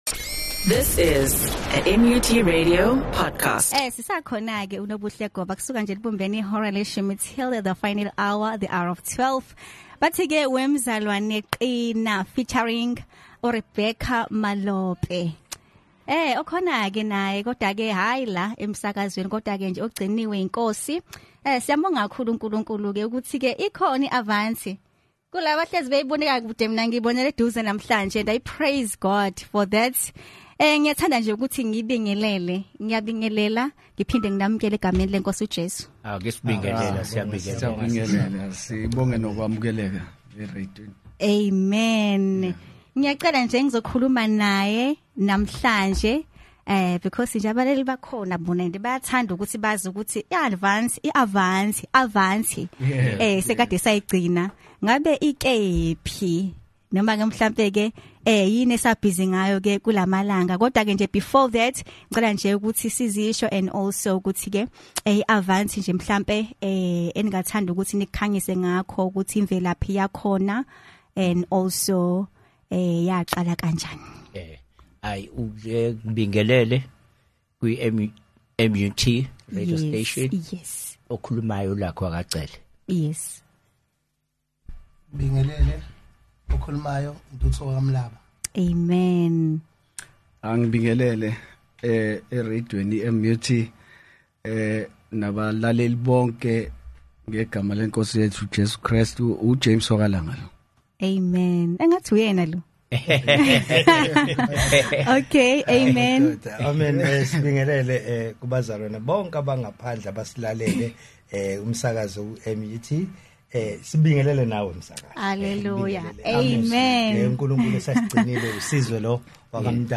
The most amazing interview we had with the South African gospel stars- Avante.